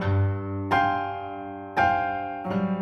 GS_Piano_85-G2.wav